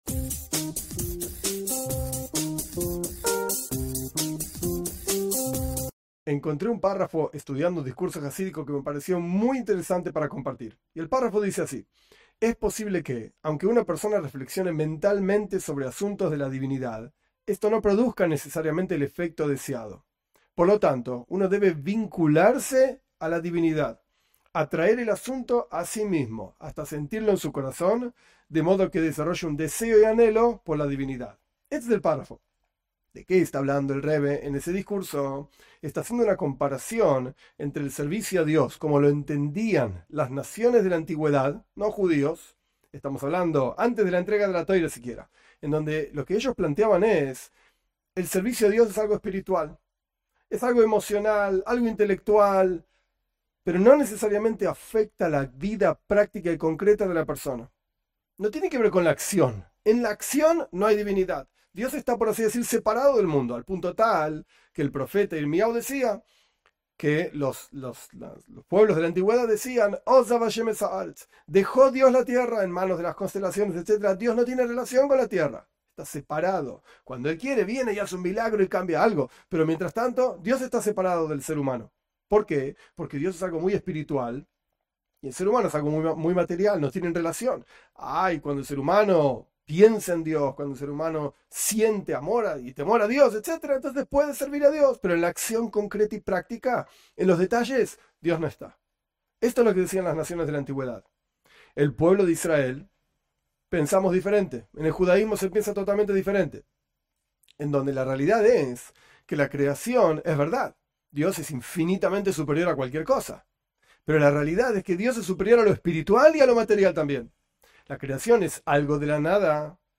Esta pequeña clase comparte un párrafo de un discurso jasídico explicando la discusión sobre el servicio a Di-s que existía entre los pueblos de la antiguedad y el pueblo de Israel. Basado en el discurso jasídico Iehi Hashem, 5745